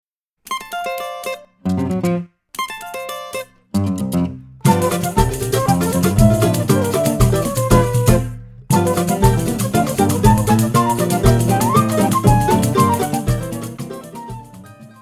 Choro brasileiro